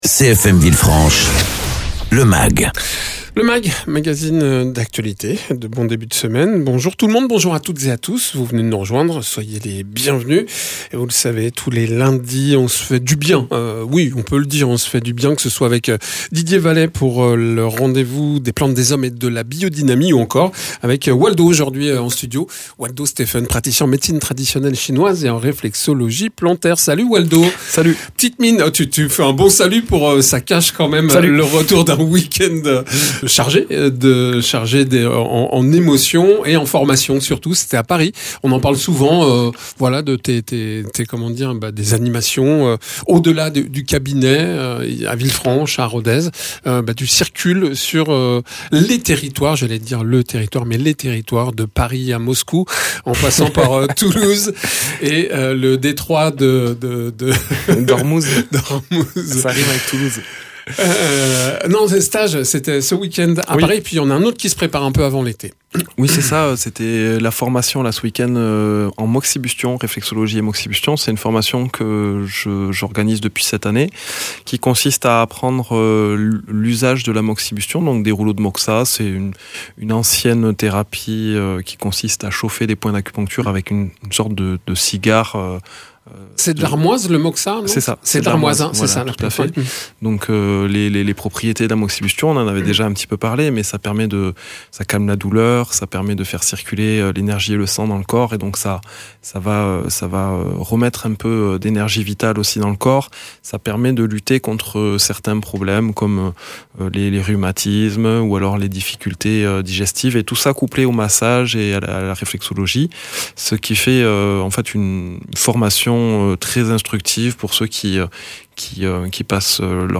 praticien en réflexologie plantaire et Médecine Traditionnelle Chinoise.